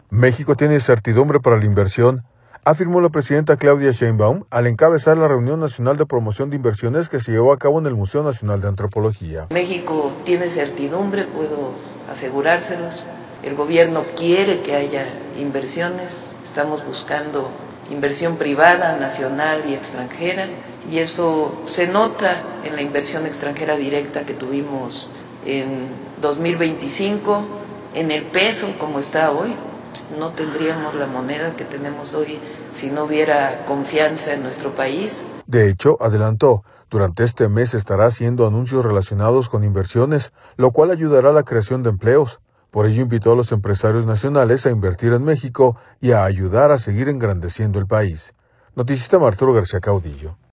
México tiene certidumbre para la inversión, afirmó la presidenta Claudia Sheinbaum, al encabezar la Reunión Nacional de Promoción de Inversiones que se llevó a cabo en el Museo Nacional de Antropología.